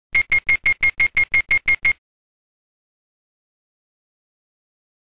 Fast Beeping